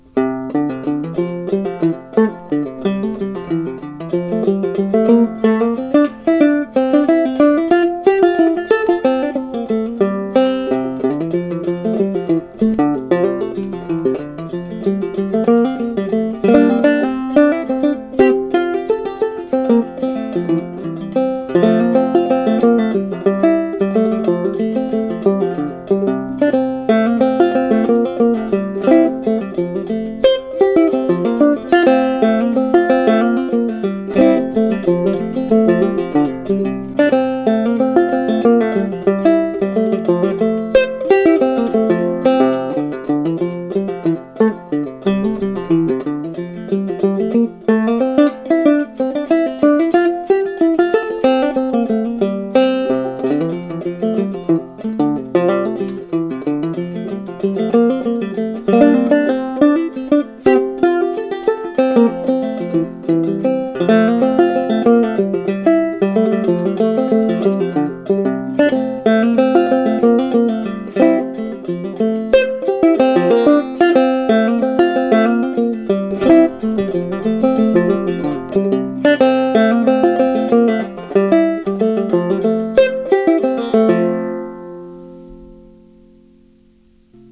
Banjo Hangout Newest 100 Clawhammer and Old-Time Songs « »